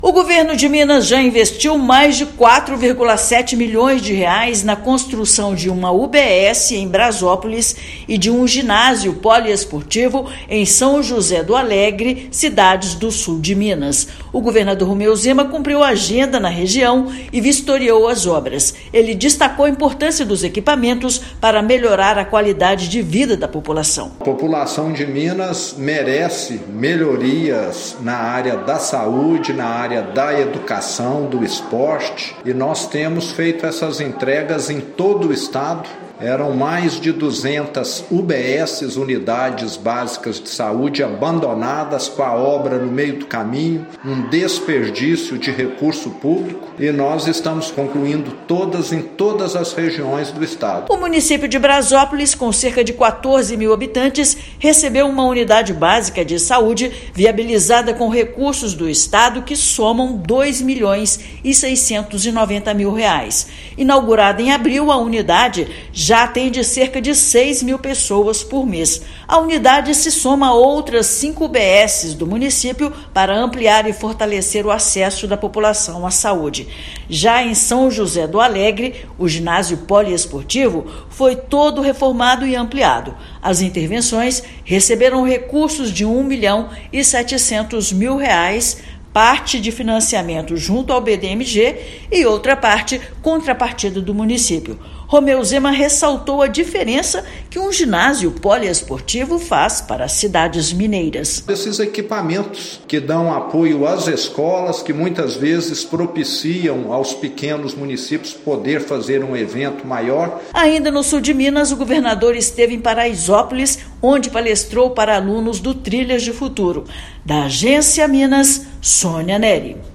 Governador vistoriou obras que beneficiam moradores de Brazópolis e São José do Alegre. Ouça matéria de rádio.